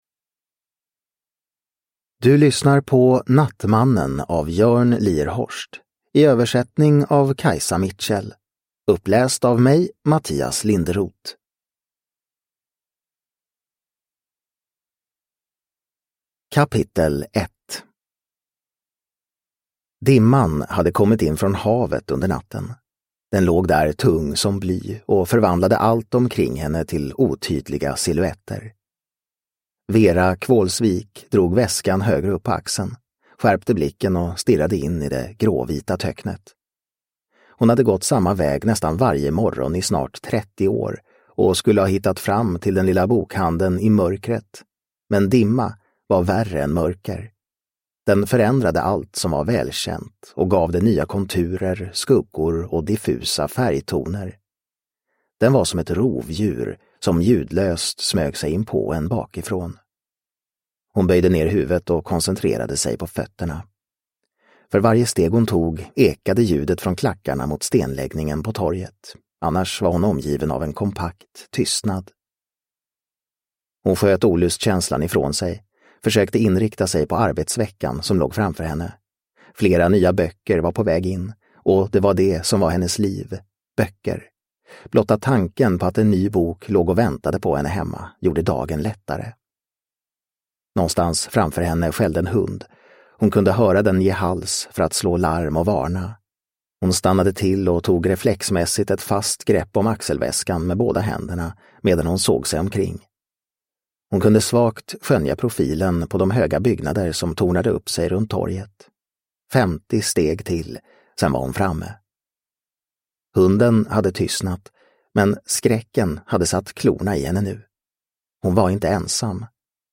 Nattmannen – Ljudbok – Laddas ner